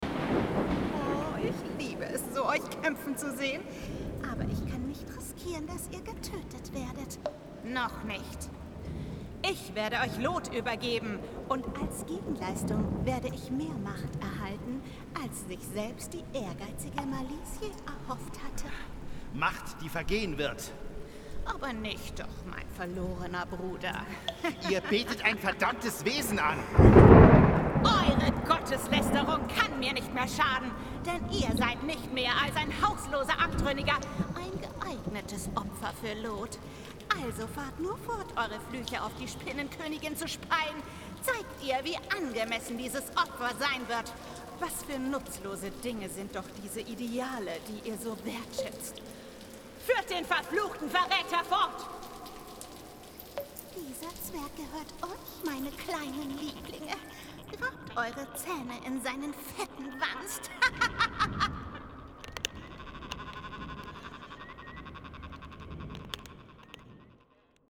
Hörspiel (Fantasy) – diabolisch, verrückt
mitteltief ⋅ frisch ⋅ facettenreich
Horspiel_Fantasy.mp3